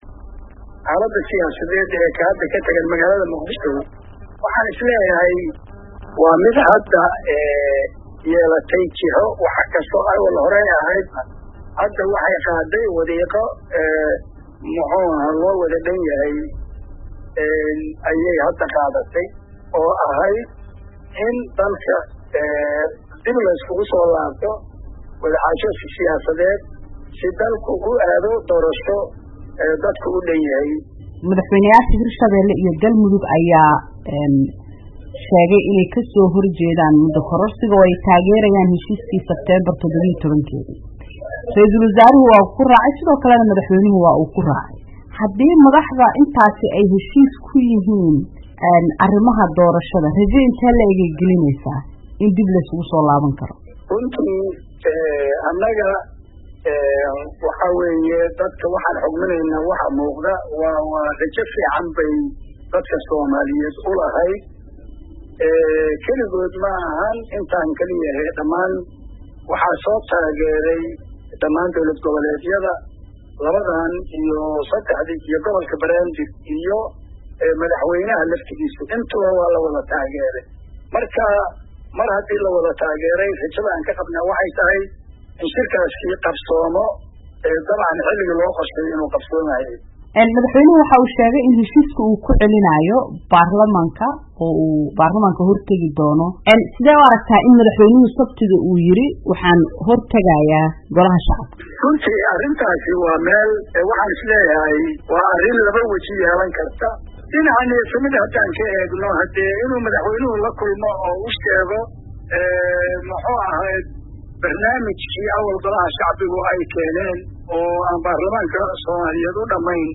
Wareysi: Cabdi Qeybdiid oo xildhibaanada uga digay isku day ka dhan ah Rooble